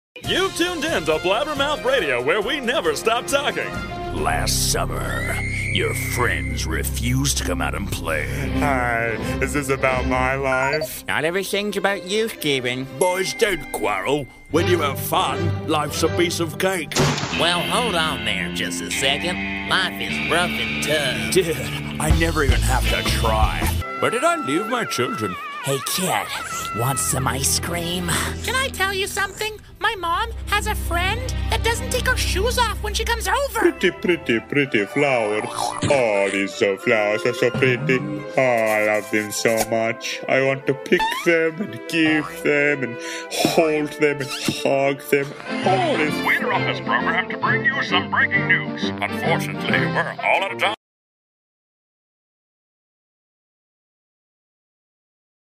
English (American)
Commercial, Natural, Reliable, Warm